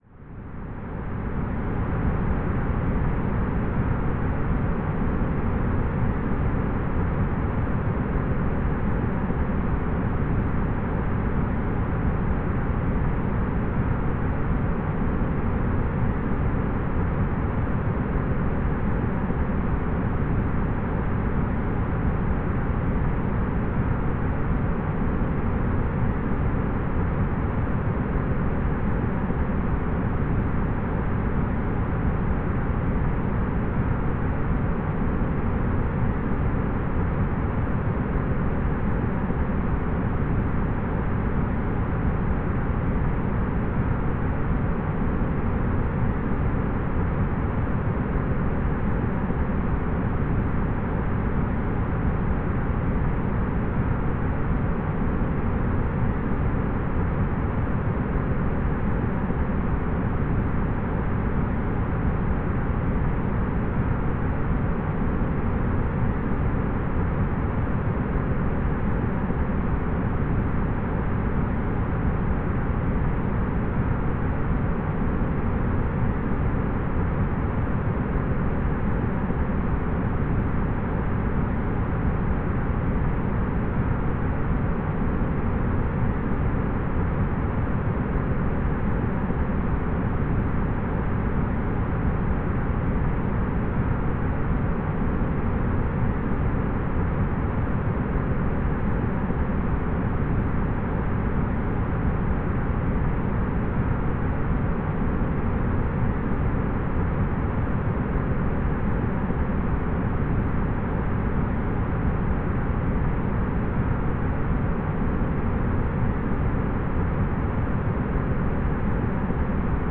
Bruit moyen faible
Dormir, Des Sons pour une bonne nuit propose des ambiances sonores spécialement composées pour vous aider à vous détendre, à vous endormir ou à vous concentrer.